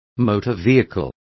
Also find out how automovil is pronounced correctly.